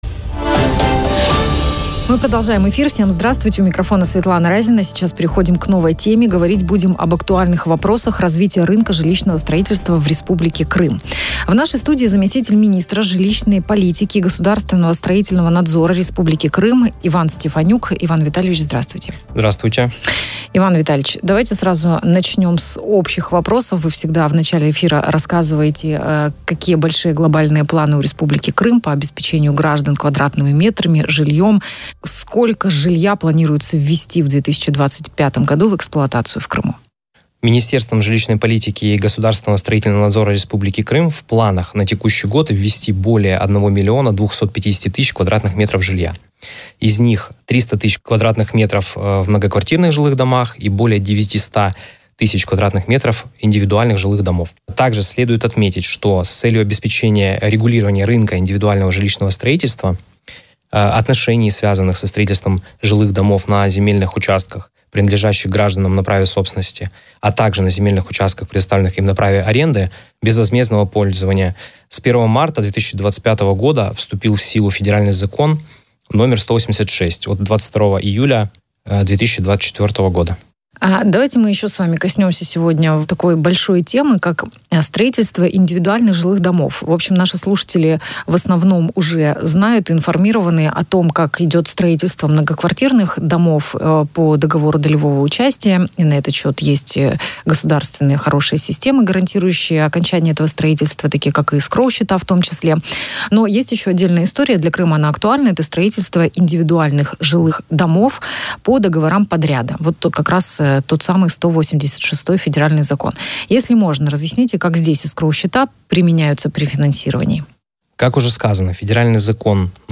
Заместитель министра жилищной политики и государственного строительного надзора Республики Крым Иван Стефанюк принял участие в эфире радио «Спутник в Крыму».
Полная версия радиоэфира